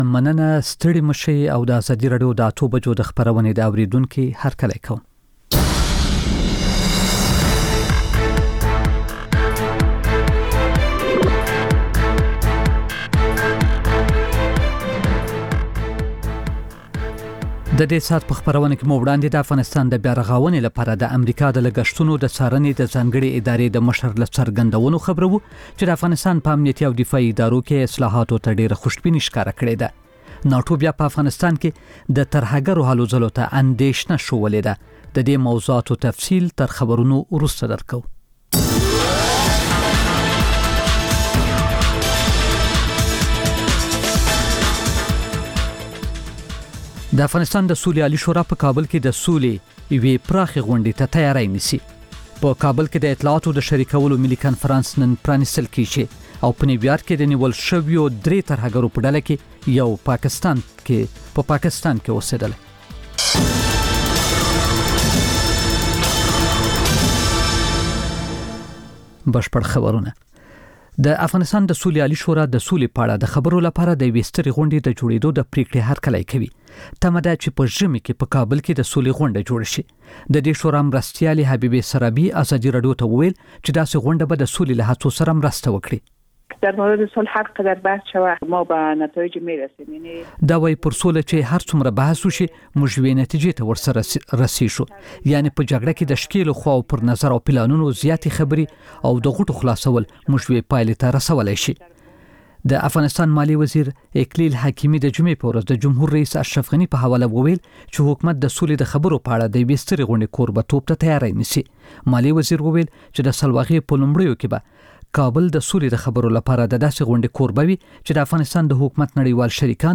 خبرونه او راپورونه، د ګوړې اچار